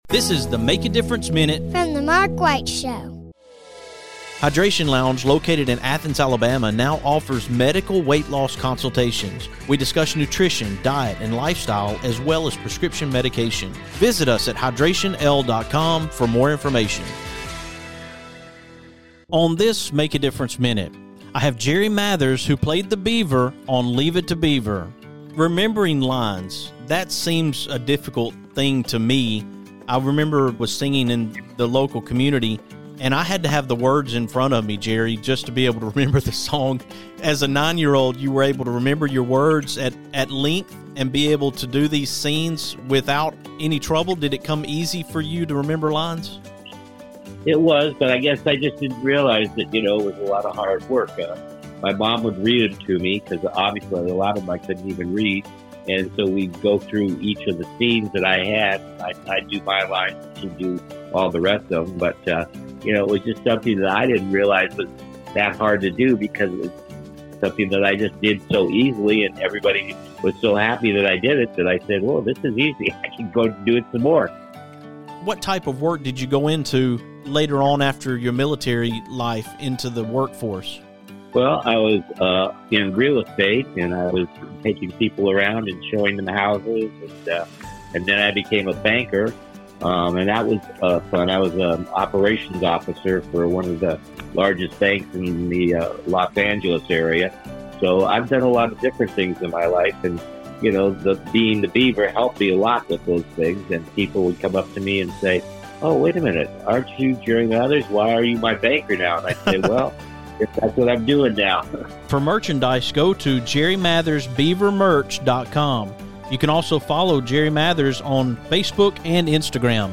But this conversation is about more than remembering lines from a beloved television show.